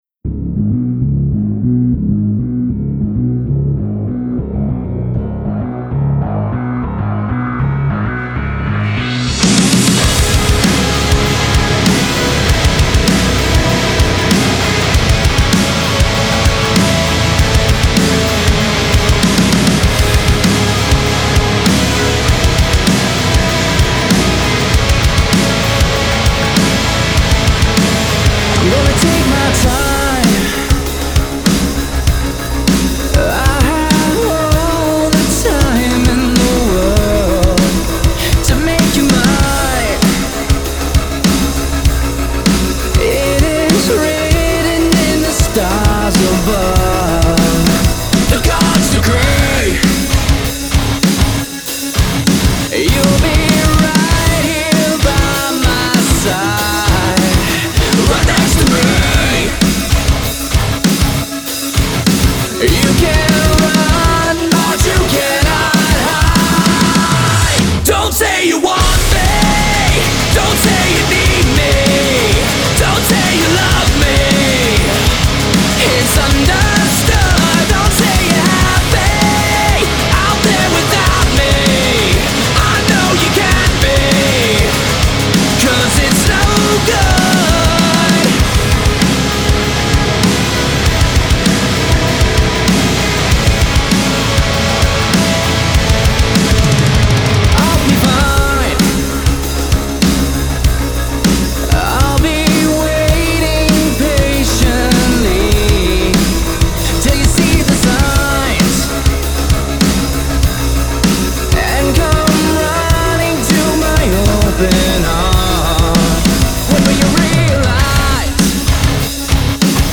five-piece metal band
blending the raw energy of metal with a modern edge.